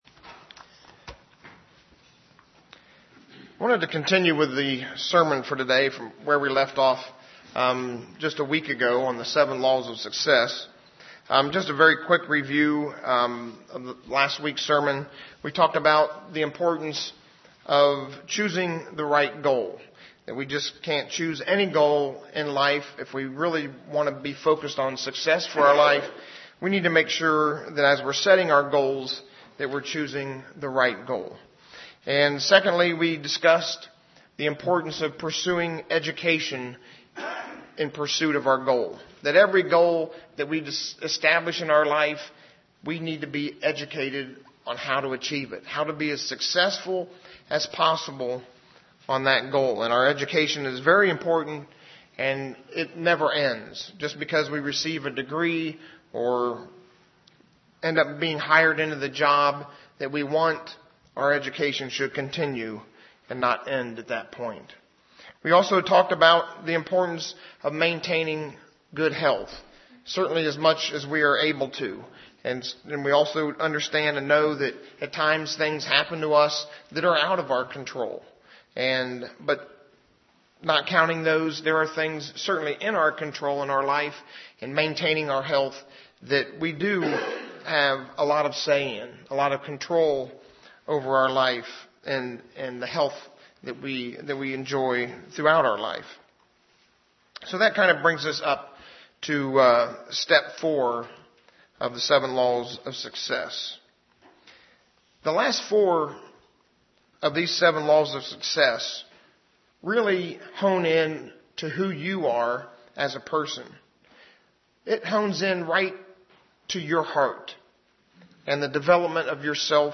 Given in Ft. Wayne, IN
UCG Sermon Studying the bible?